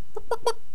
chicken_select3.wav